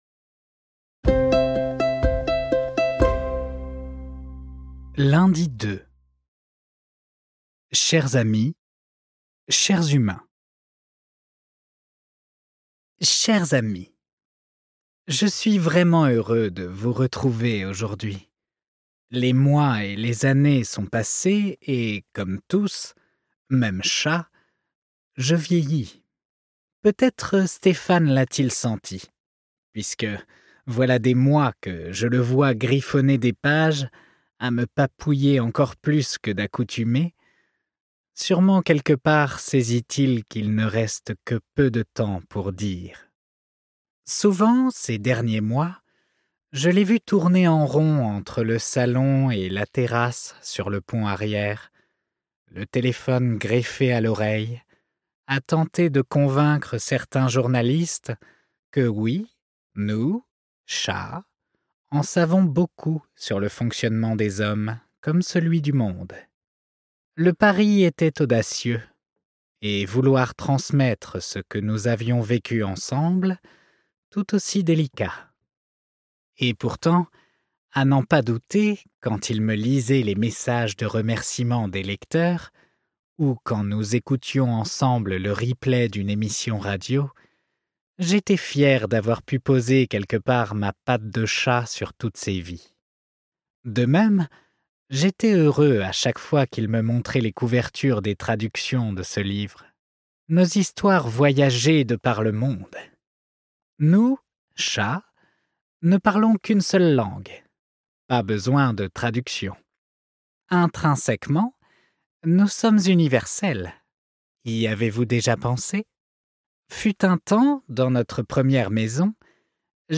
Ce livre audio est interprété par une voix humaine, dans le respect des engagements d'Hardigan.